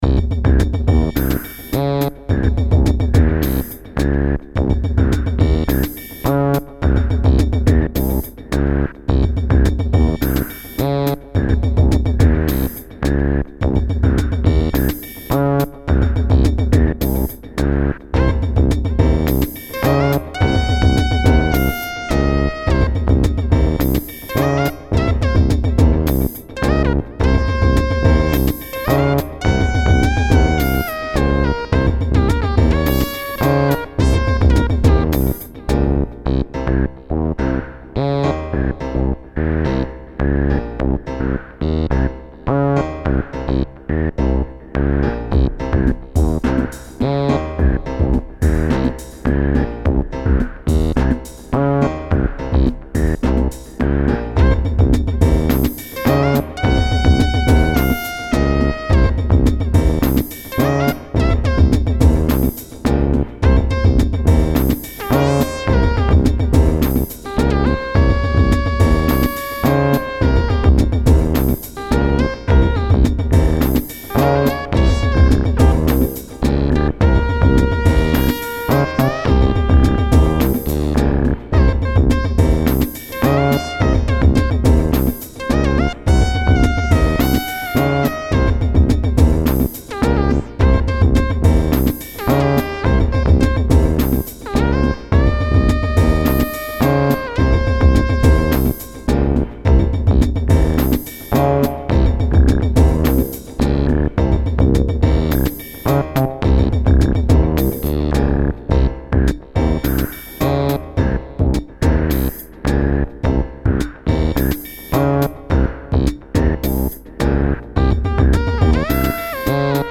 Pieza de Electro-Jazz
Música electrónica
melodía rítmico sintetizador